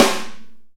soft-hitclap.mp3